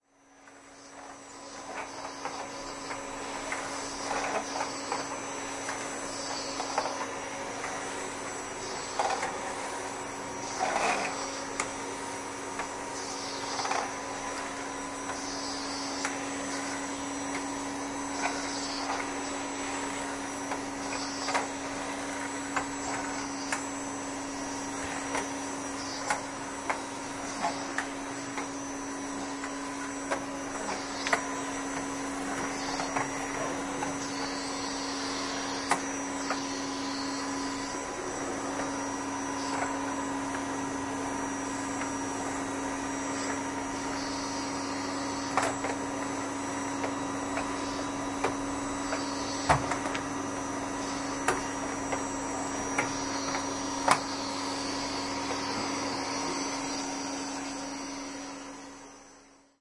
描述：记录吸尘木地板。
Tag: 真空 清洗 胡佛 地板 清洁